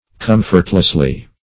Com"fort*less*ly, adv.